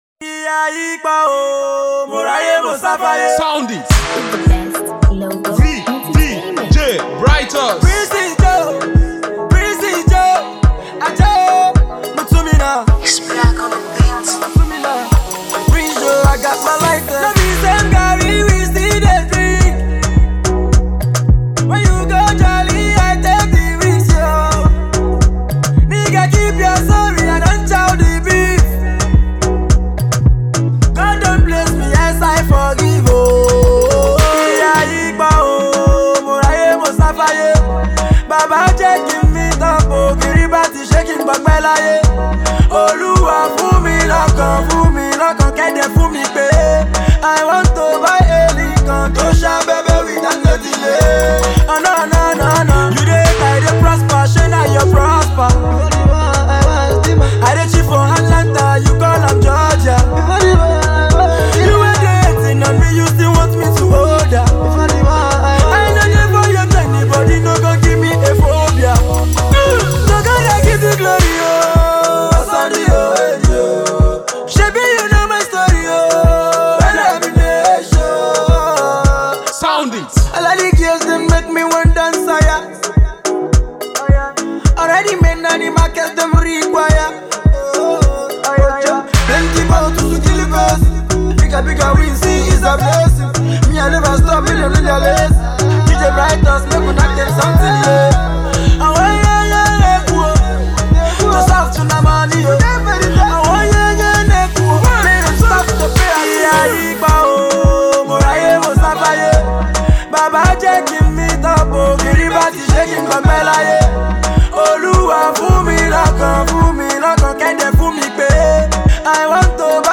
Afro dance tune
thumping new banger